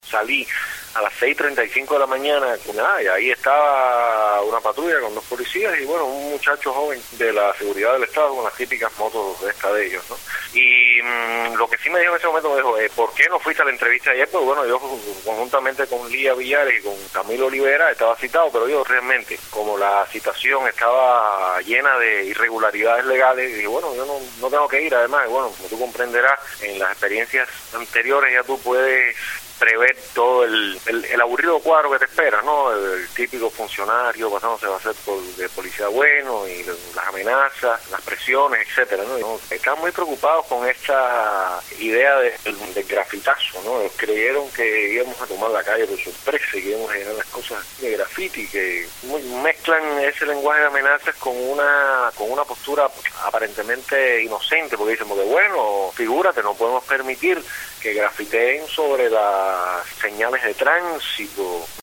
Posteriormente conversó con Radio Martí.